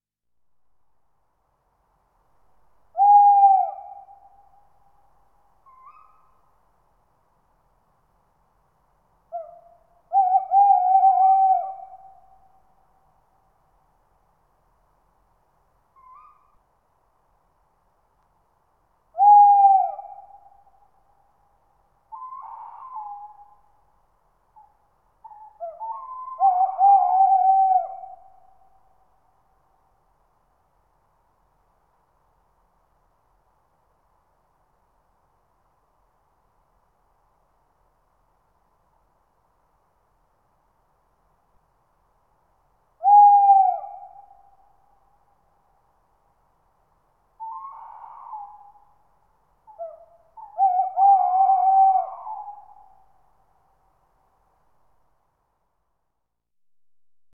Waldkauz Ruf
• Er ruft nachts mit einem tiefen, klagenden „Huu-huu“, das oft in Filmen verwendet wird.
Waldkauz-Ruf-Voegel-in-Europa.wav